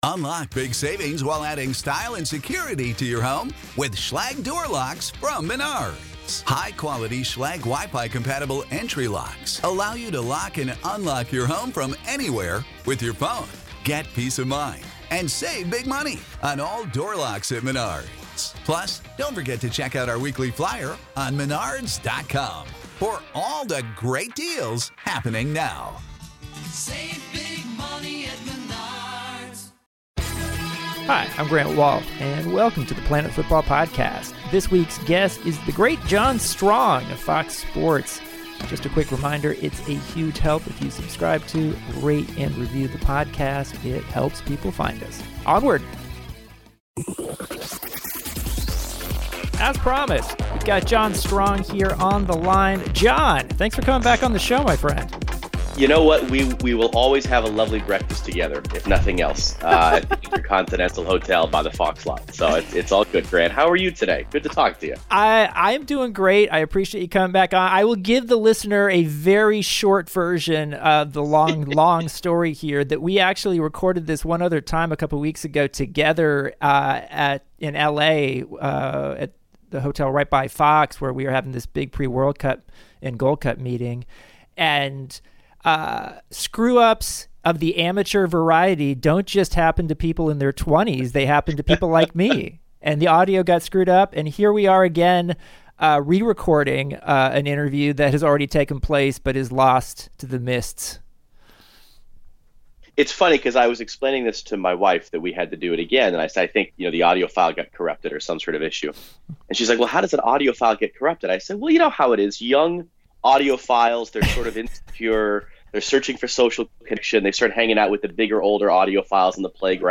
A Conversation With John Strong of Fox Sports
Grant has a fun discussion with John Strong, the lead play-by-play voice of Fox Soccer, about what it’s like to reach your professional life goals in your early 30s, why he took singing lessons for work, his thoughts on MLS and this summer’s Gold Cup, and what it’s like to share the same name with a prominent adult film actor.